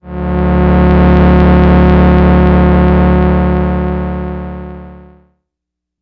Moog Classic 02.wav